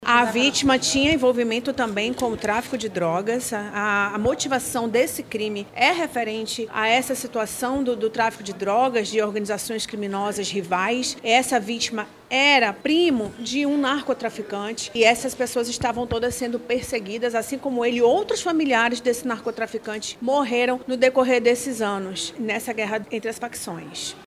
Em coletiva de imprensa realizada nesta quarta-feira (19)